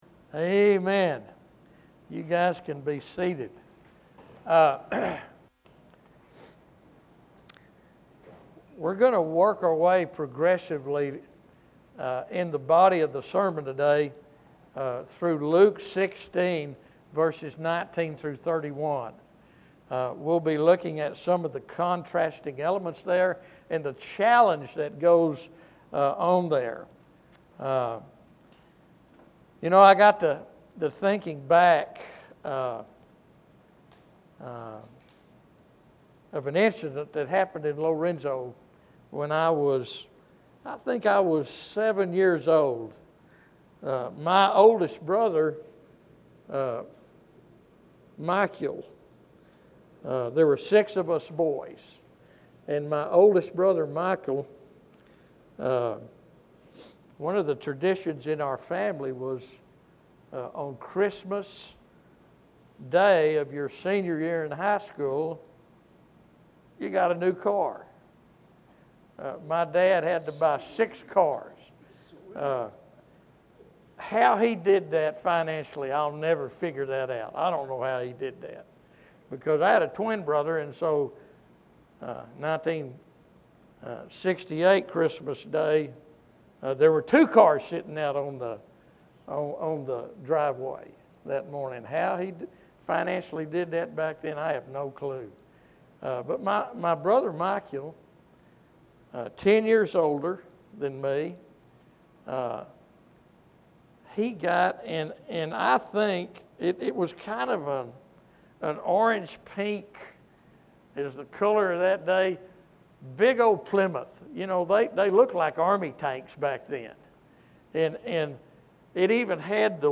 In this week's message